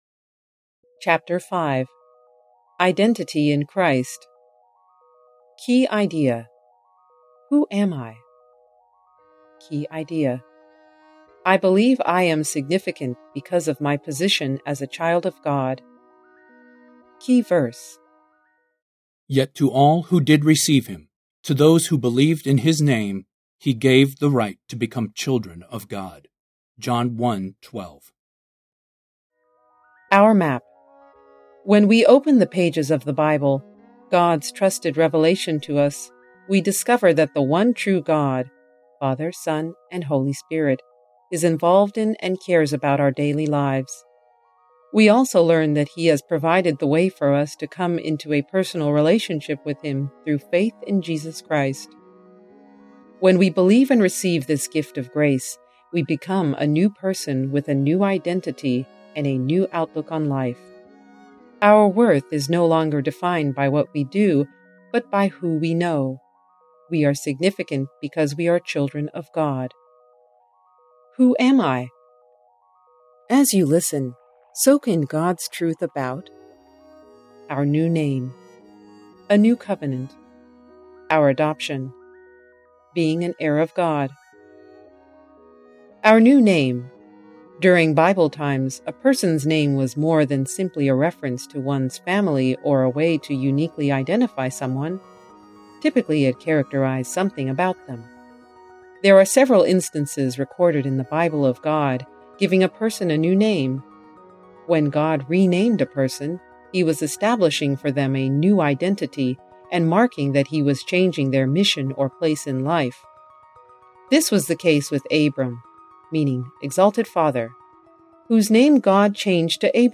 Sermon Player: Believe Audio Book: It is one thing to know the story of the bible it is another thing to live it.